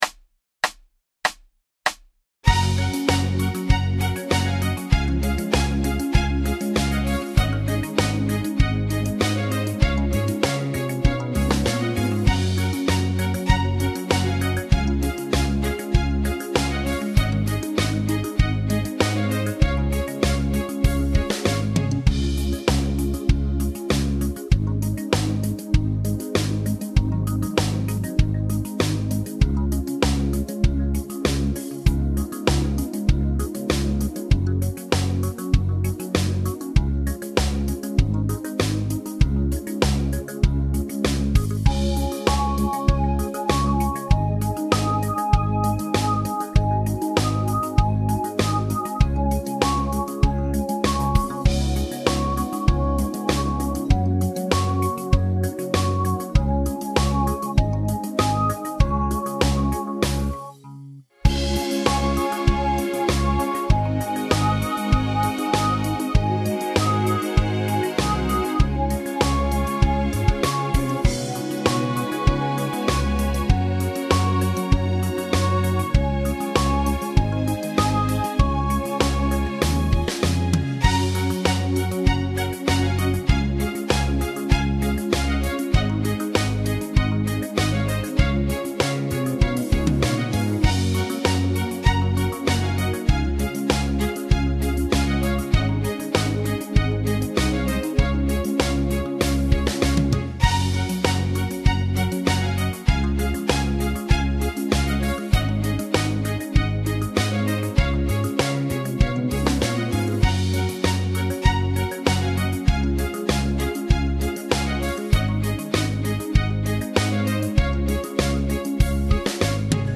Genere: Moderato
Scarica la Base Mp3 (3,65 MB)